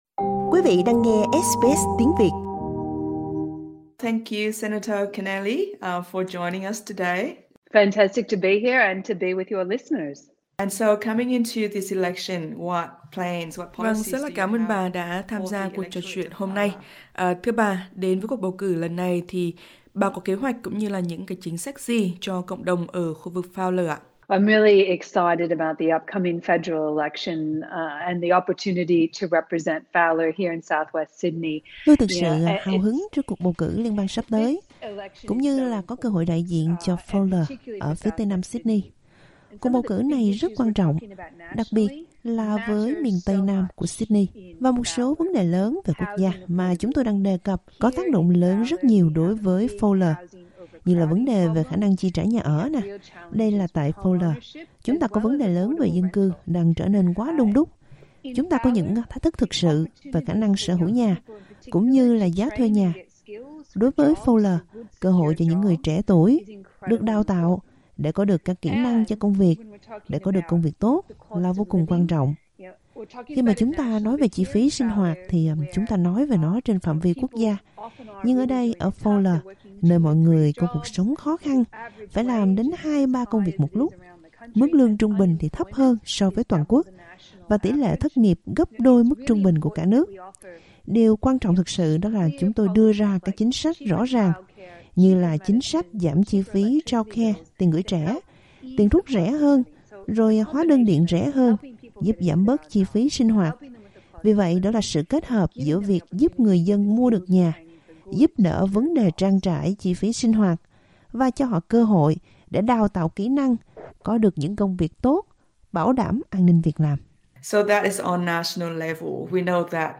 SBS Tiếng Việt phỏng vấn bà Kristina Keneally.